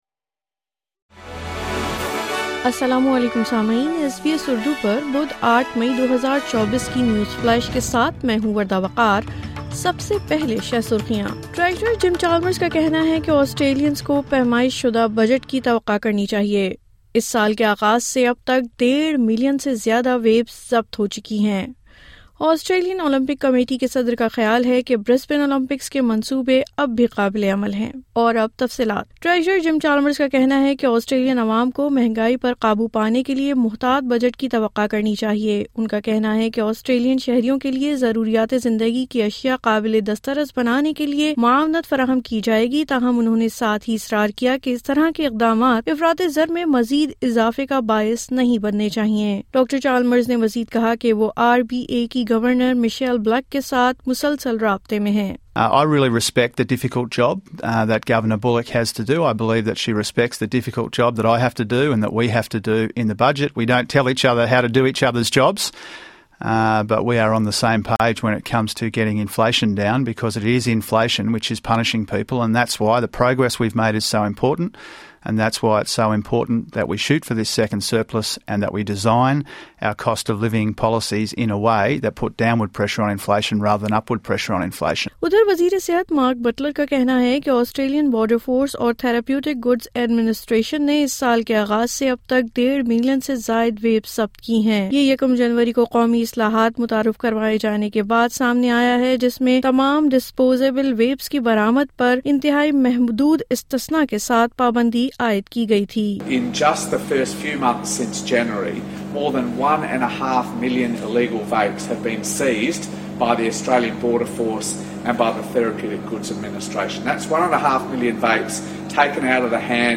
نیوز فلیش:08 مئی 2024: ٹریژرر جم چالمرز کے مطابق آسٹریلینز ایک پیمائش شدہ بجٹ کی توقع کر سکتے ہیں